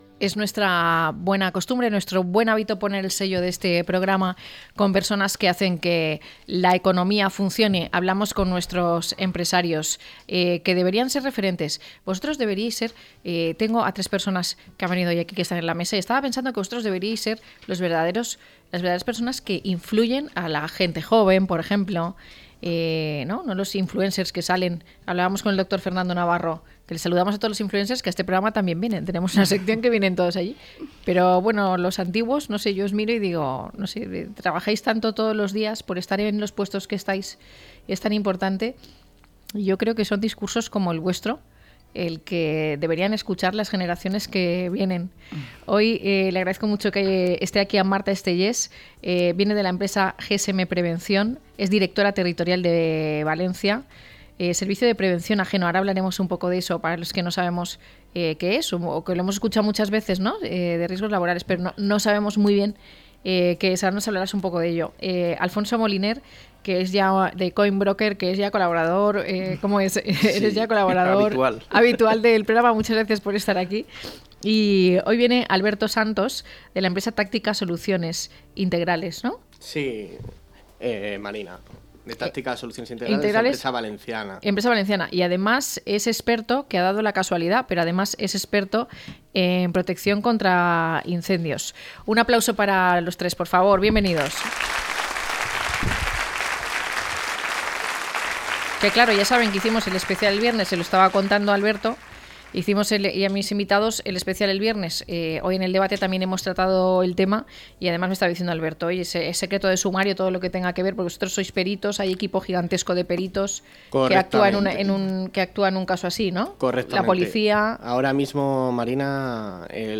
0226-LTCM-TERTULIA.mp3